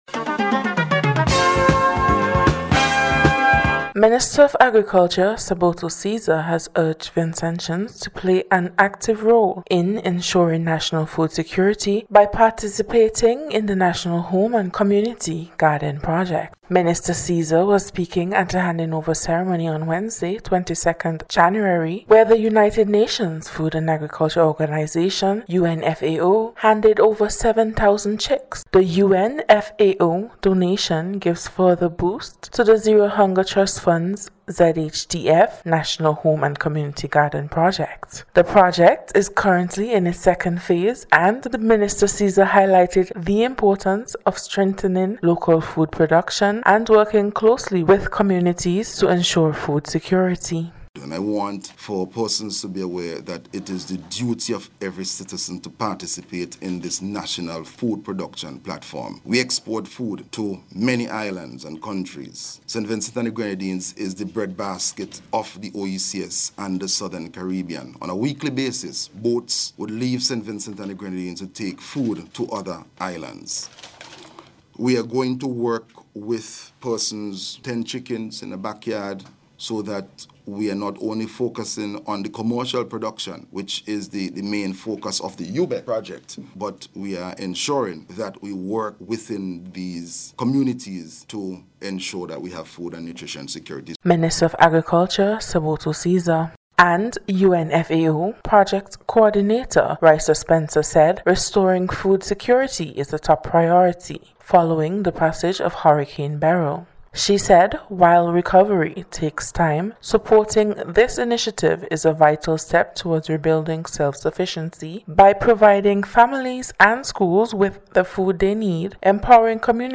NBC’s Special Report- Friday 24th January,2025
ZHTF-FAO-CEREMONY-REPORT.mp3